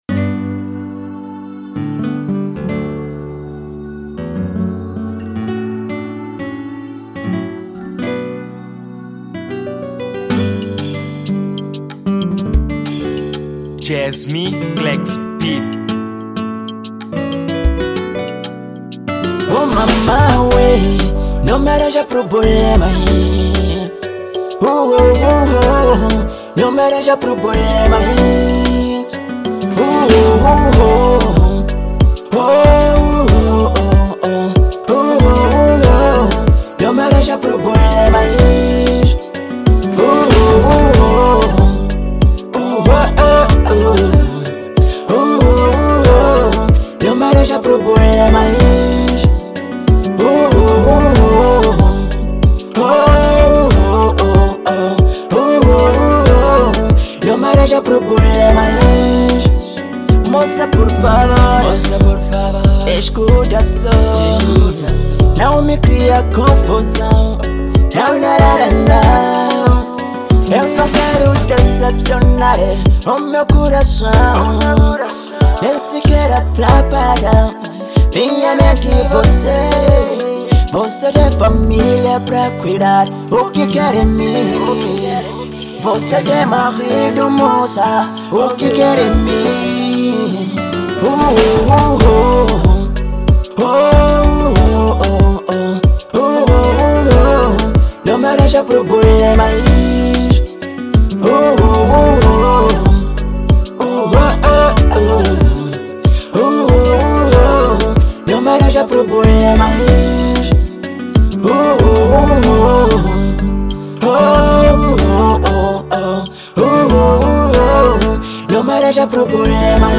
| Kizomba